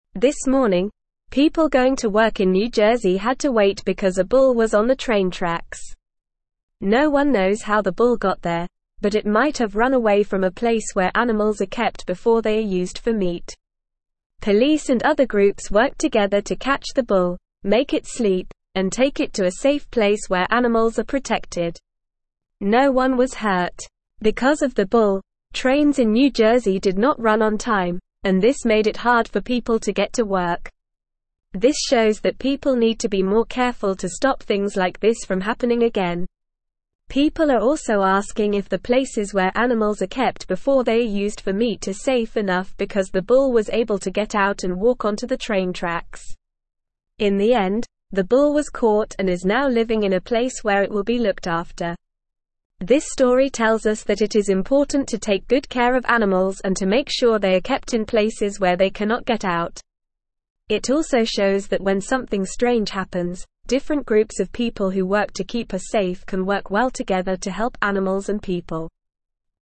Normal
English-Newsroom-Lower-Intermediate-NORMAL-Reading-Bull-on-Train-Tracks-Causes-Trouble-Gets-Help.mp3